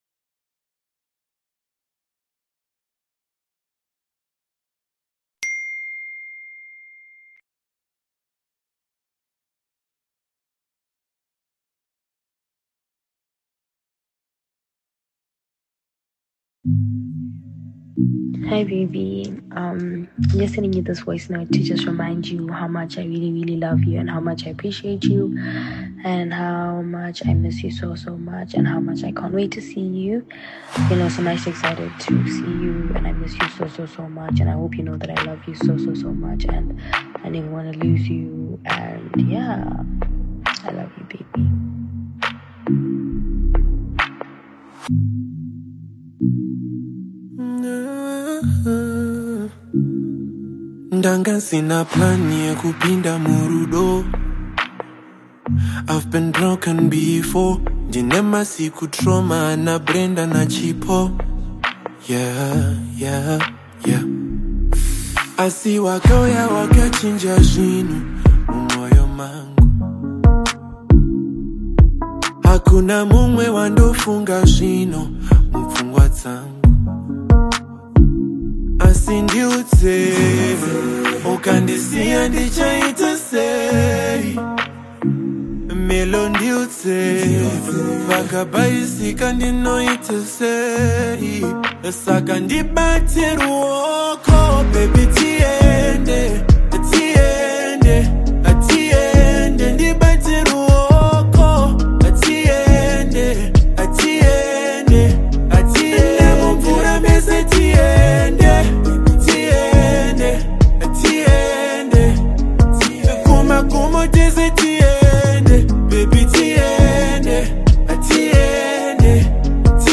smooth Afro-beat/urban love single